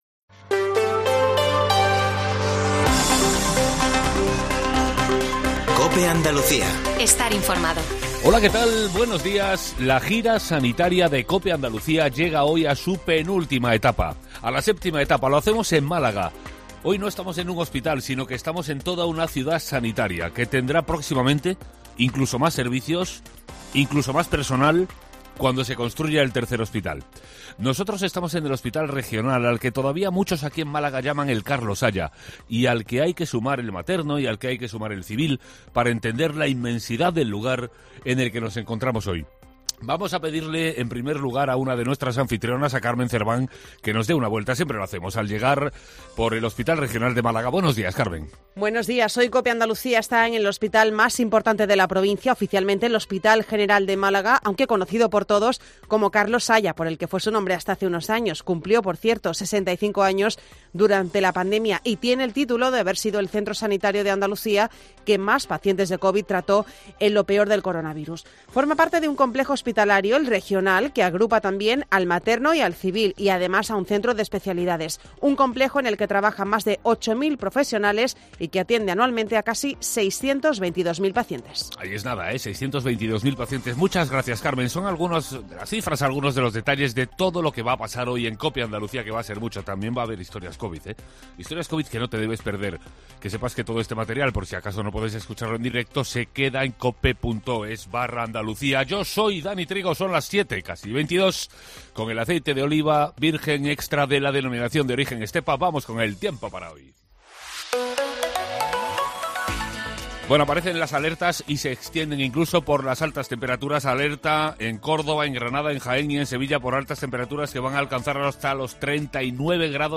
Herrera en COPE Andalucía 07.20 - 19 de mayo - Desde el Hospital Regional de Málaga